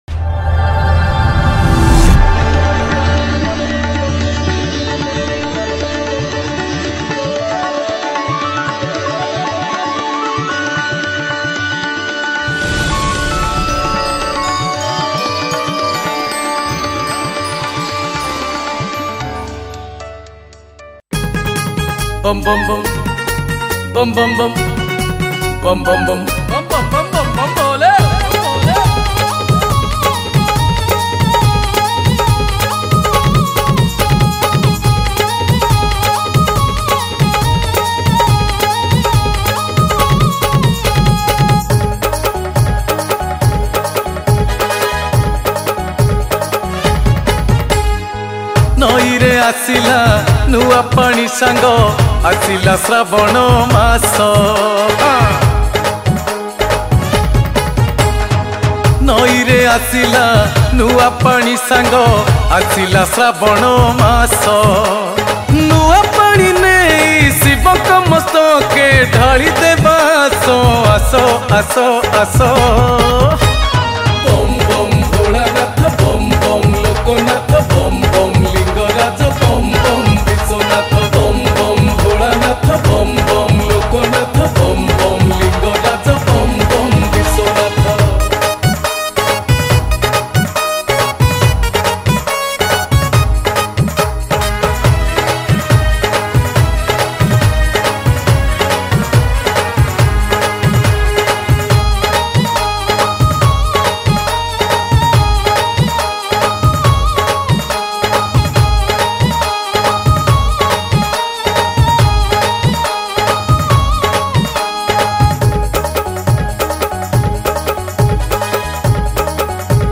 • Category :Bolbum Special Song